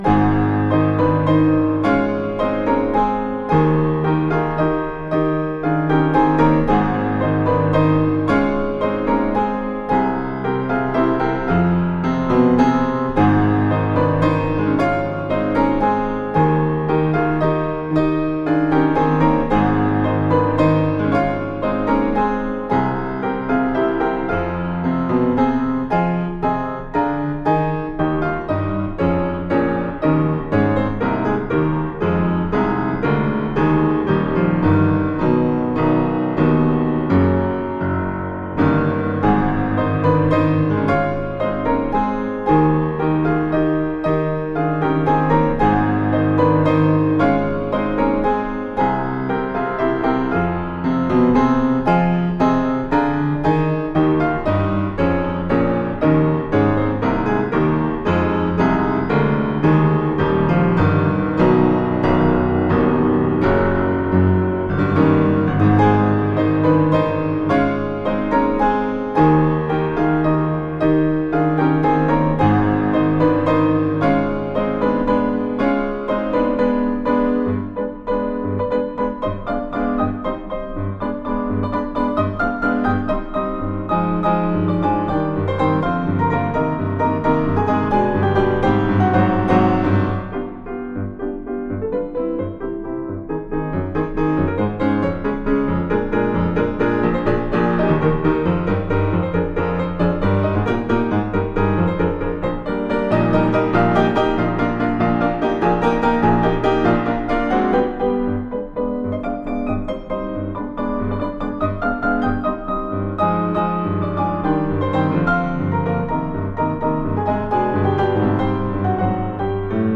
Virtuoso piano.